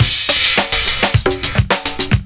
106PERCS04.wav